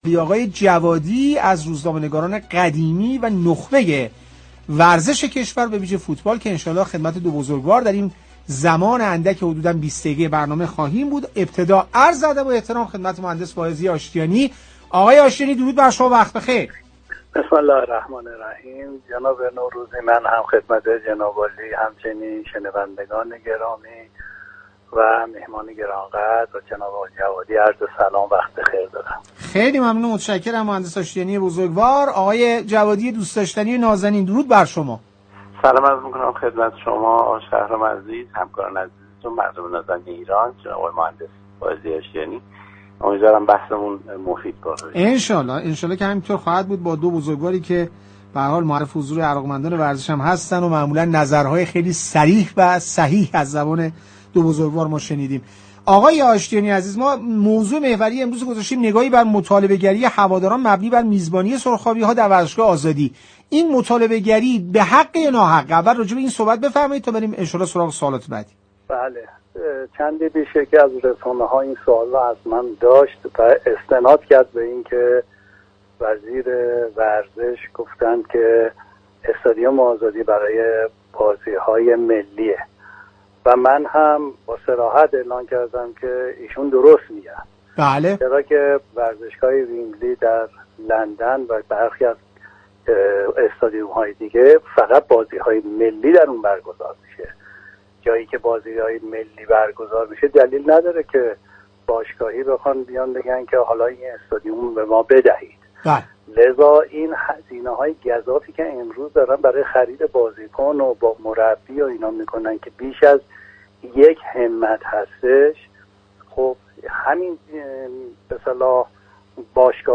كارشناسان در برنامه «تهران ورزشی» رادیو تهران با تأكید بر جایگاه ملی ورزشگاه آزادی، ساخت استادیوم اختصاصی برای استقلال و پرسپولیس را ضروری دانستند و ورزشگاه تختی را گزینه‌ای موقت معرفی كردند.